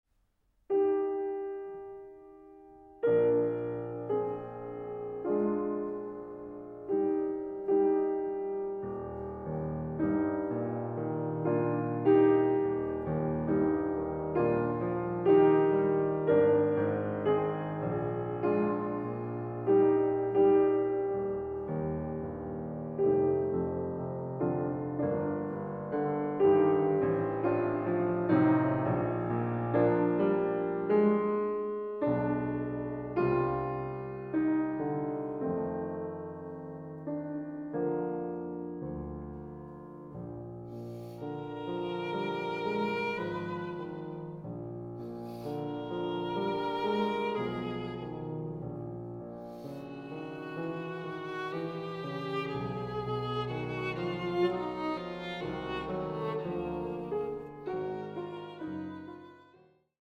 Sonatas for Piano and Violin
Piano
Violin